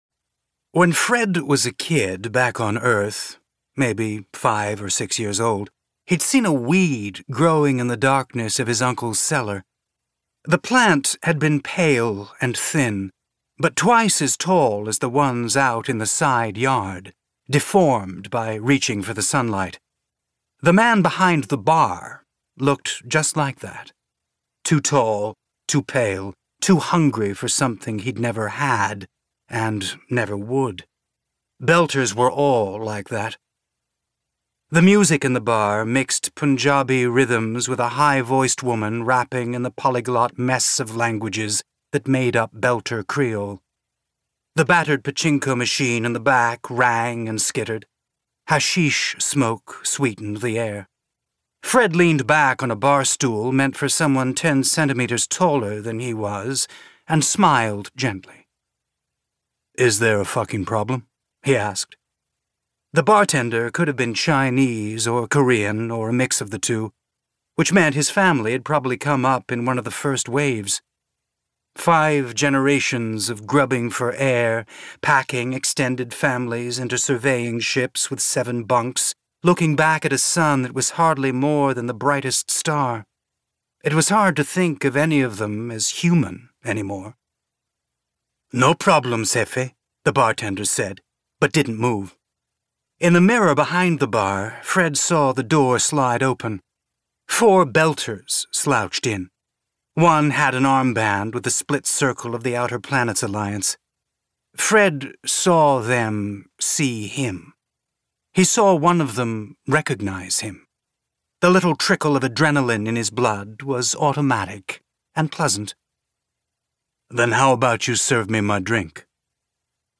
drop/books/audiobooks/James S. A. Corey - The Expanse (complete series)/narrated by Jefferson Mays/0.5 - The Butcher of Anderson Station (novella)